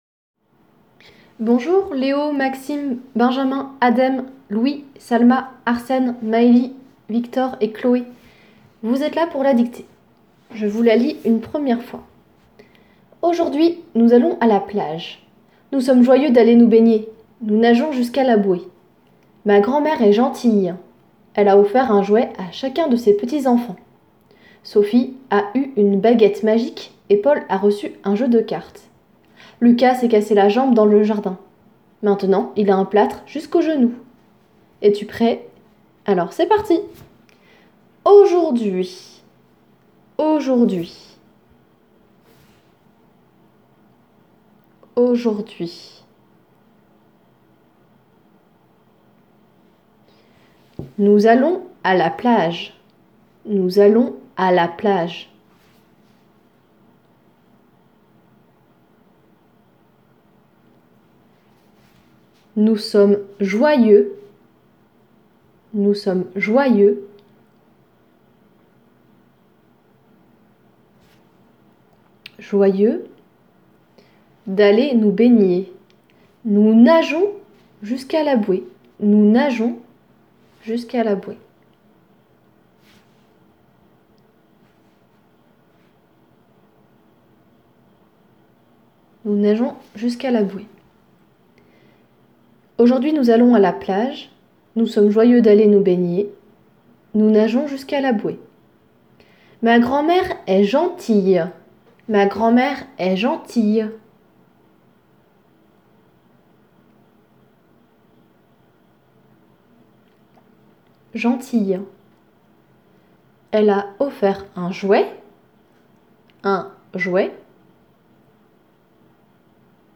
La dictée :
Dictee_lundi_11_mai.m4a